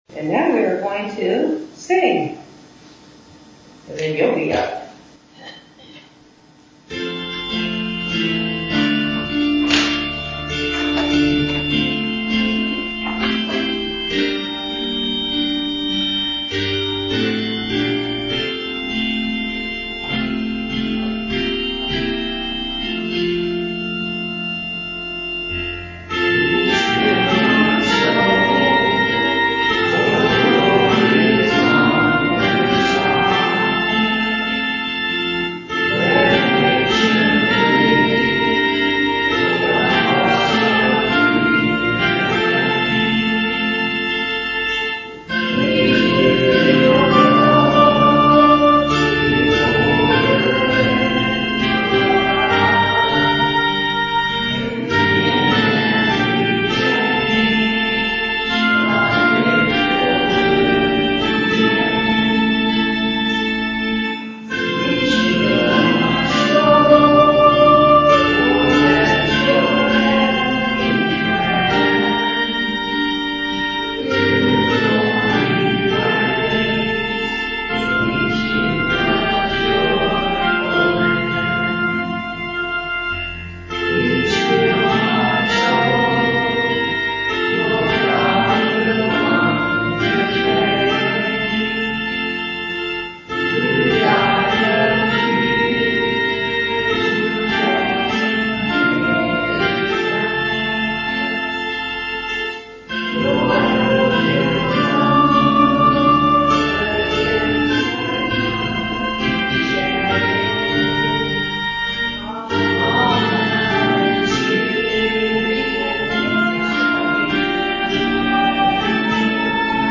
Bethel Church Service
Sharing Prayer Concerns